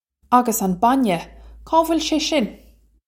Uggus on bon-yeh... kaw vwil shay shin?
This is an approximate phonetic pronunciation of the phrase.